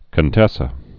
(kən-tĕsə, kōn-tĕssä)